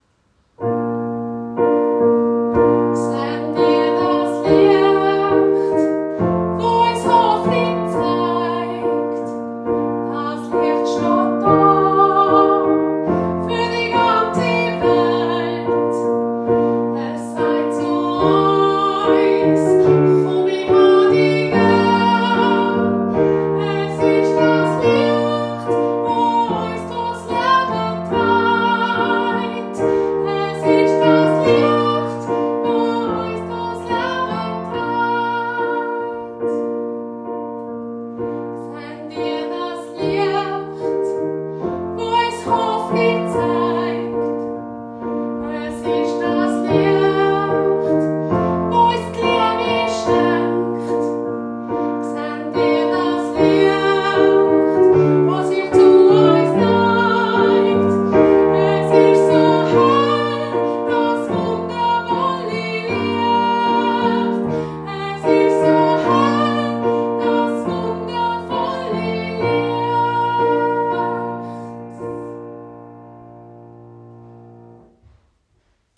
Lieder zum Üben für den Kinderchor